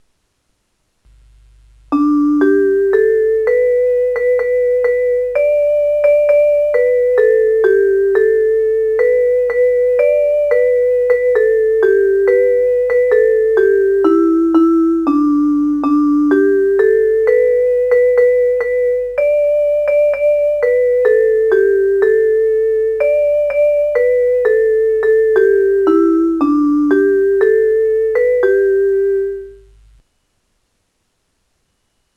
演奏・歌唱　( 楽譜と違っているかもしれません。)
演奏　mp3